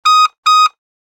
Machine Alert Beep Sound Effect
A short and sharp warning beeping tone. Beep sounds.
Machine-alert-beep-sound-effect.mp3